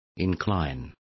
Complete with pronunciation of the translation of inclining.